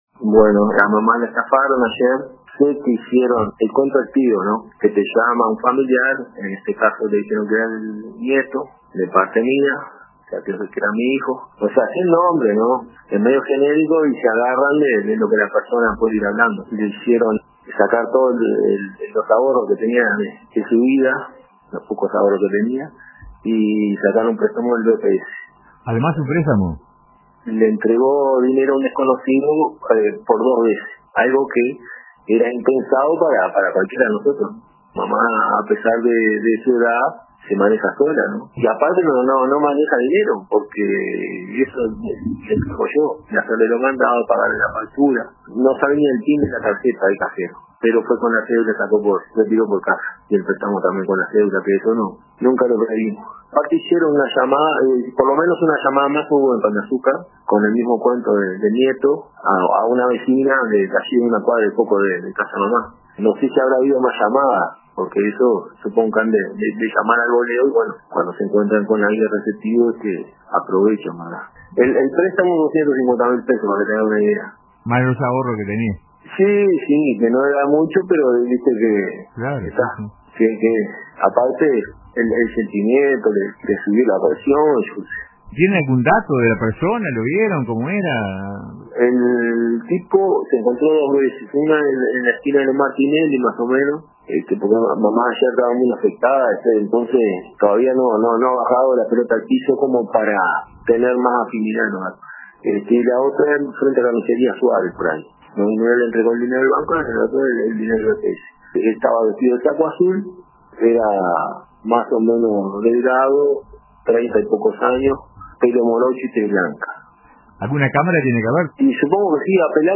brindó detalles en una entrevista en Pan de Azúcar en Sintonía.